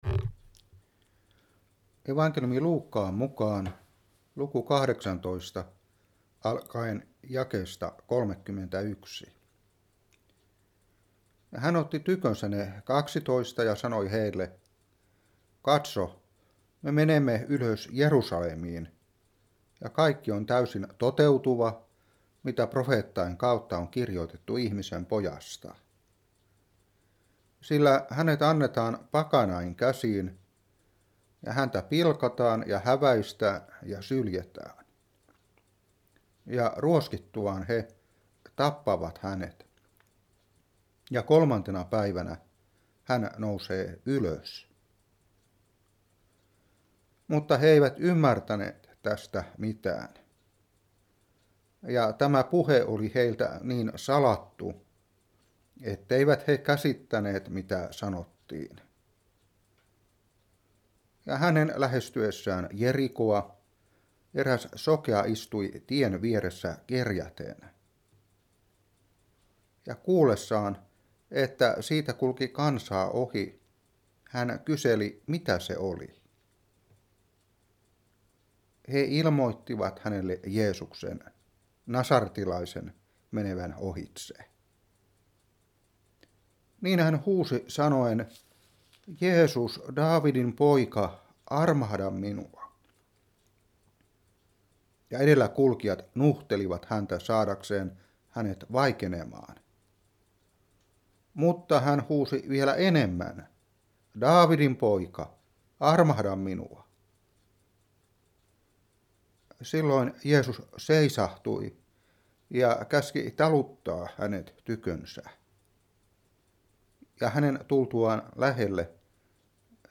Saarna 2016-2.